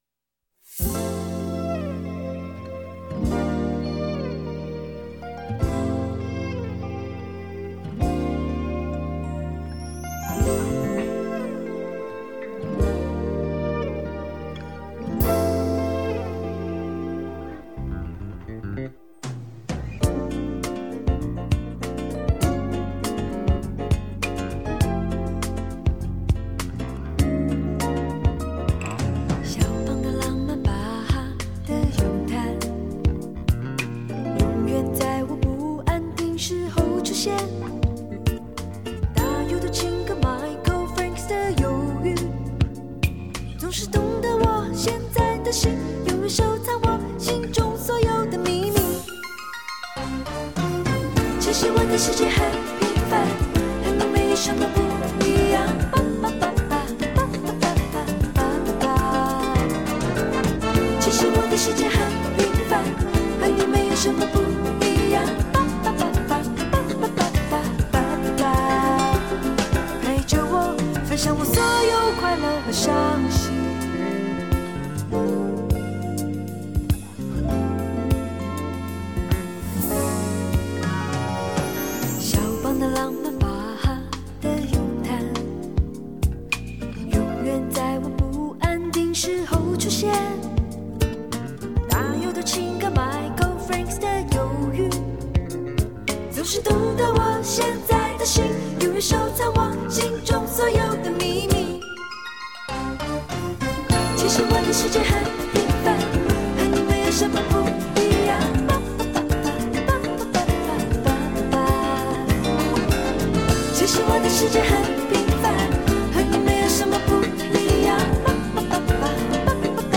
这也就不奇怪为什么专辑中许多歌曲在编曲上带着一股淡淡的Jazz味儿。